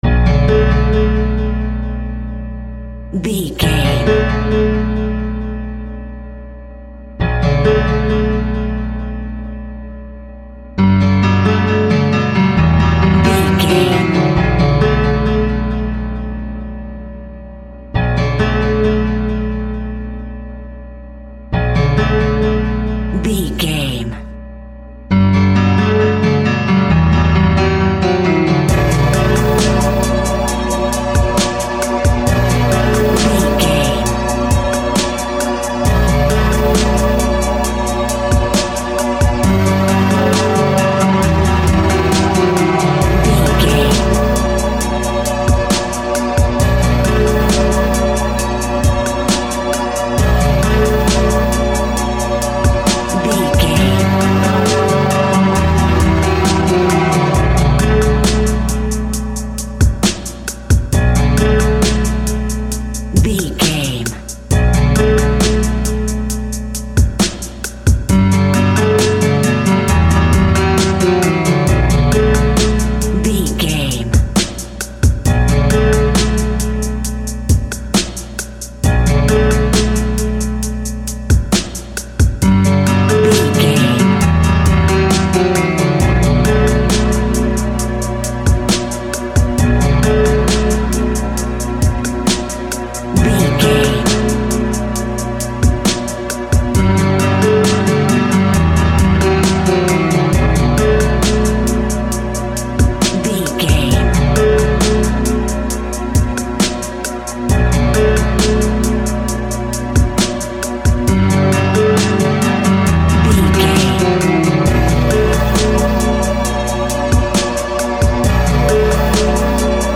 Modern Epic Video Game Chart Music.
Aeolian/Minor
hip hop
chilled
laid back
hip hop drums
hip hop synths
piano
hip hop pads